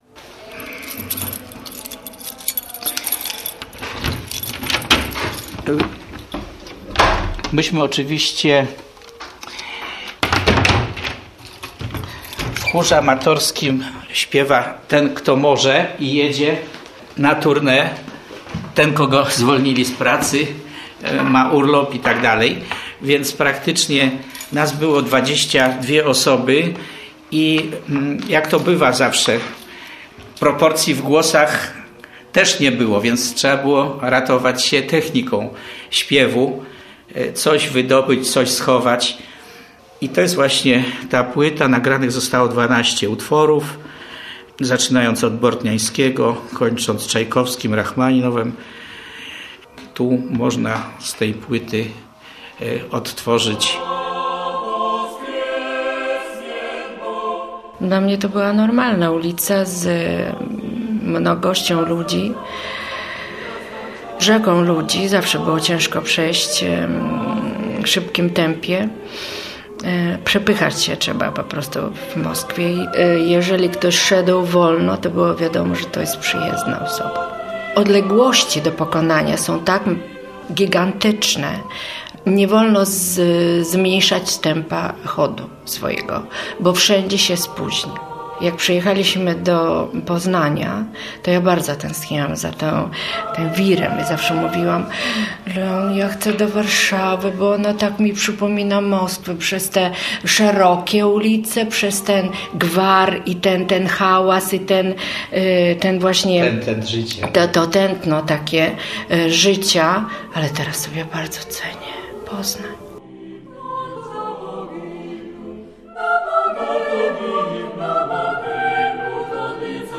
b93po7kq6fs5itf_reportaz_slowianska_dusza.mp3